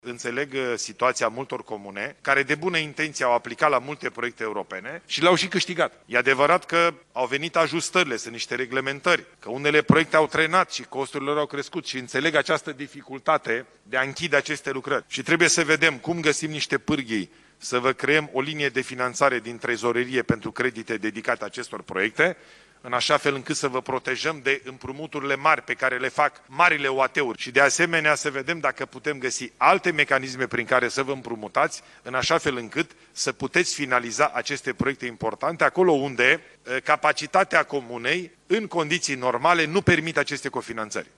Taxele locale vor fi modificate de anul viitor, anunță premierul, la dezbaterea cu primarii locali din țară care are loc la Palatul Parlamentului.